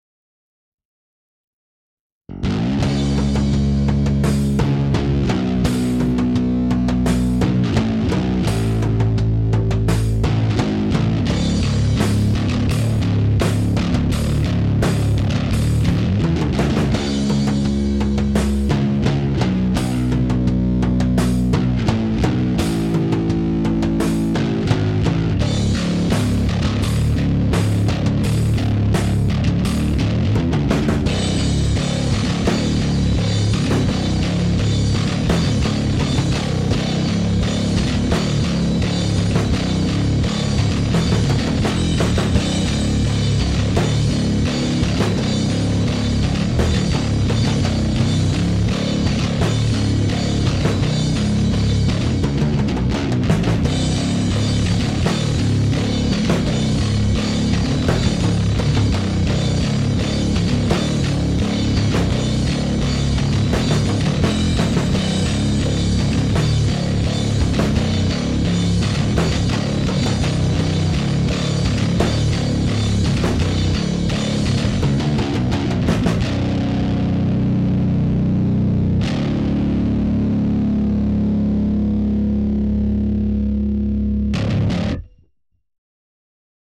Hier mal ne neue kurze Aufnahme von eben. Einfach nur KVLTdrums, bissl Reverb drauf und der Bass-Track ist der Various (mit einzelnem EMG MMTW) auf Singlecoil geschaltet mit gestern neu eingestelltem Sound. In dem Fall gefällt er mir mit Singlecoil fast besser als mit Humbucker. Knurrt mehr mittig Jetzt erstmal FInger etwas ausruhen.
Anhänge DOOOOM DrumsAndBass.mp3 1,3 MB